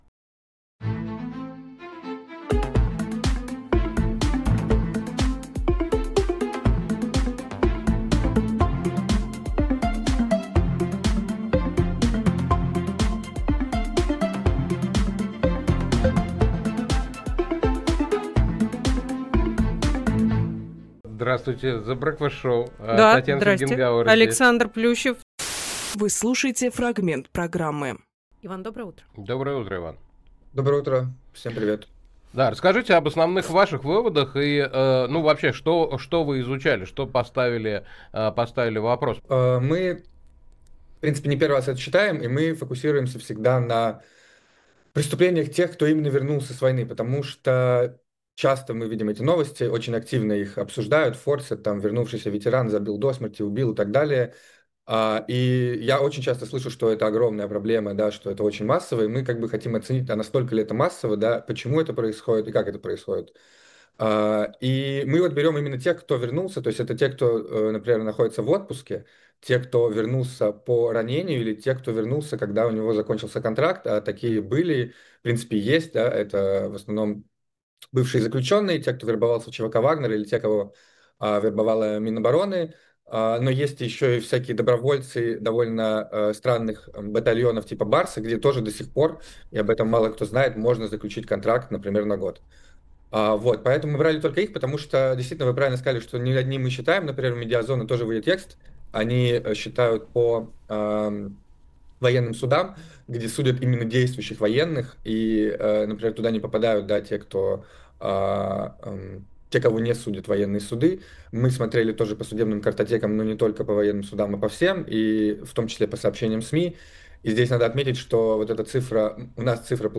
Фрагмент эфира от 09.12.25